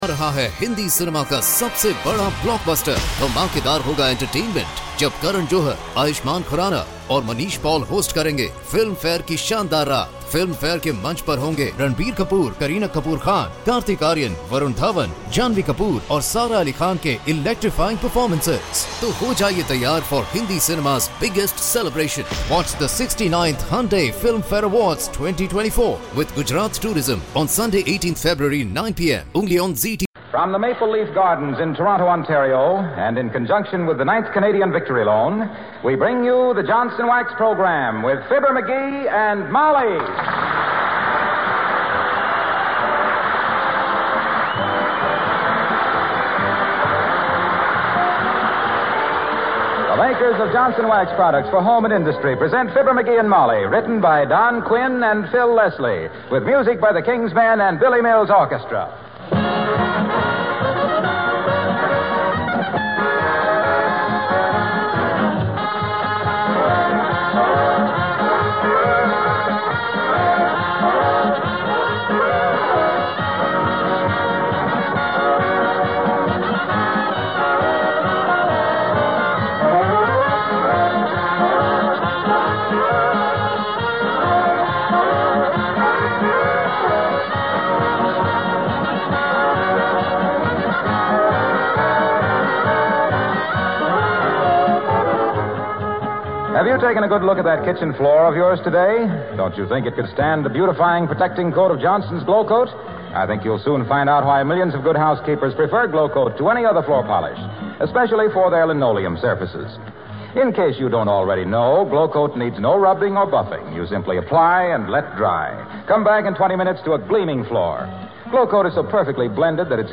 Fibber McGee and Molly was an American radio comedy series.
The title characters were created and portrayed by Jim and Marian Jordan, a real-life husband and wife team that had been working in radio since